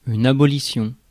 Prononciation
Synonymes annulation cancelation abolissement abrogation Prononciation France: IPA: [a.bɔ.li.sjɔ̃] Le mot recherché trouvé avec ces langues de source: français Traduction 1.